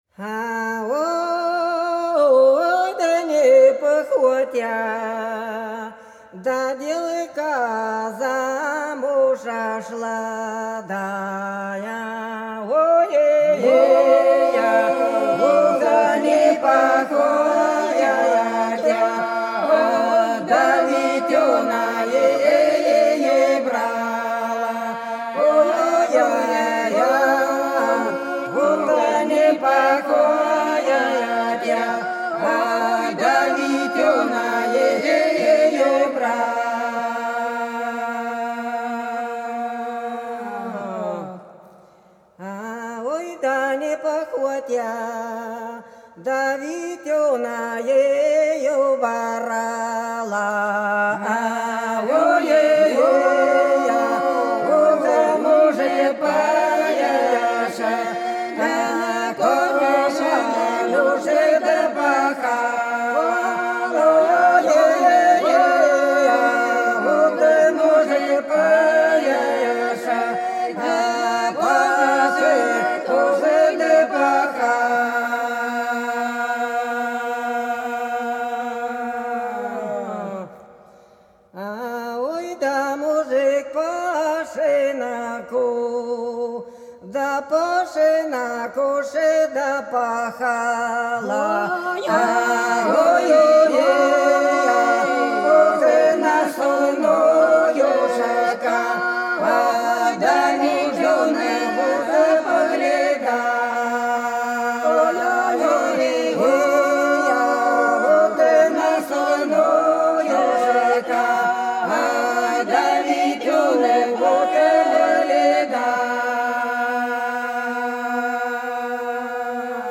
Белгородские поля (Поют народные исполнители села Прудки Красногвардейского района Белгородской области) Не по охоте девка замуж шла - протяжная